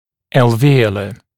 [ˌælvɪ’əulə] [ˌæl’viːələ][ˌэлви’оулэ] [ˌэл’ви:элэ]альвеолярный